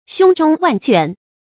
胸中萬卷 注音： ㄒㄩㄥ ㄓㄨㄙ ㄨㄢˋ ㄐㄨㄢˋ 讀音讀法： 意思解釋： 謂讀過大量的書。